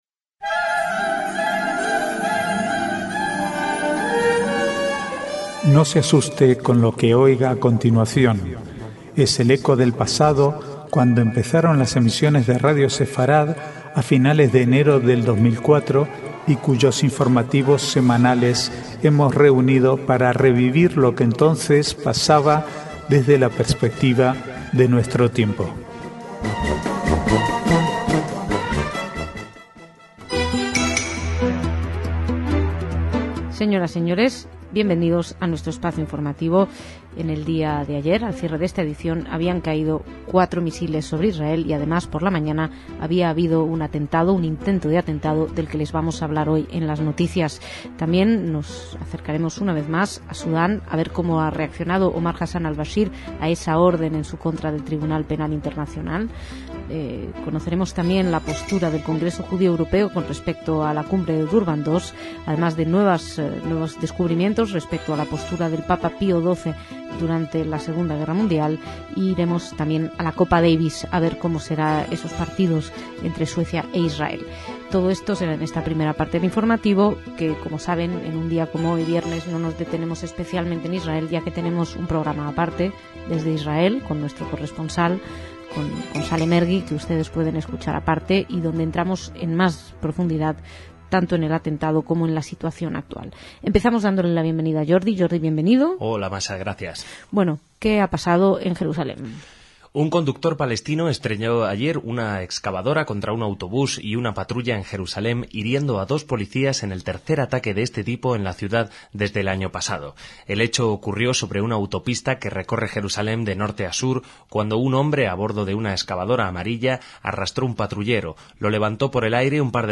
Archivo de noticias del 6 al 11/3/2009